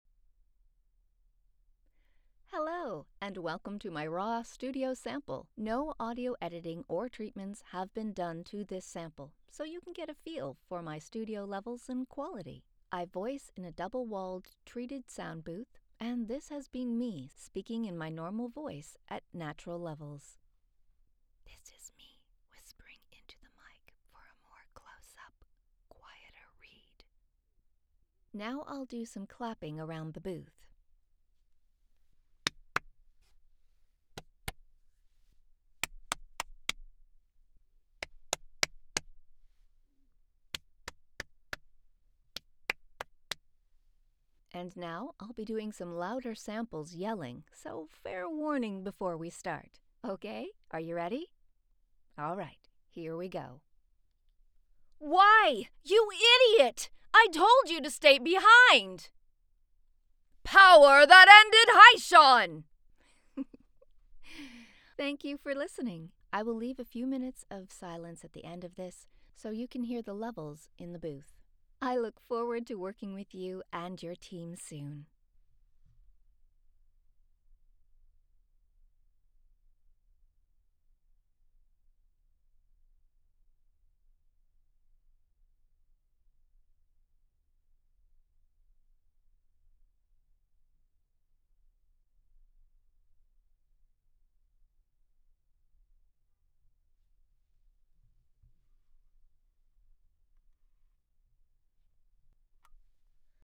My vocal range spans from warm and conversational to bold and theatrical—ideal for everything from corporate explainers to high-energy animation.
Double Walled Bear Cave Sound Booth
Microphones: Neumann TLM 102, Stellar Vintage, Sure SM7b with Cloudlifter
Raw Studio Sample